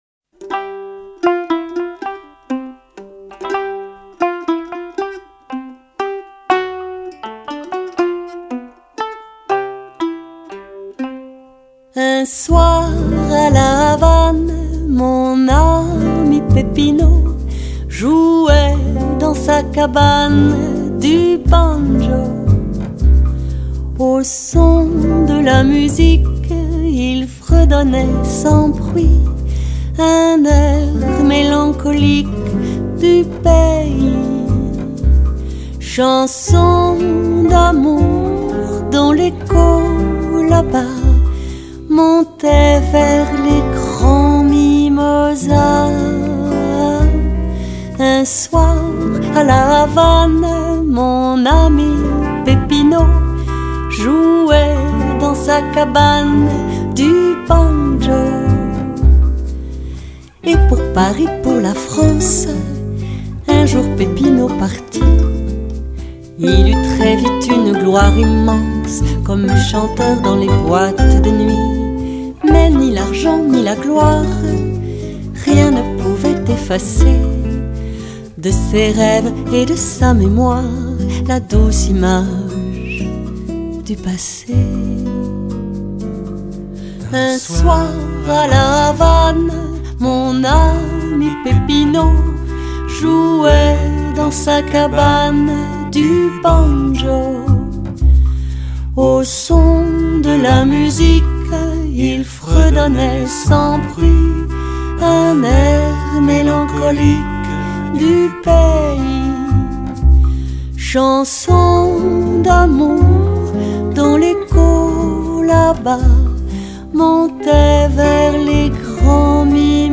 le rythme berçant et hawaïen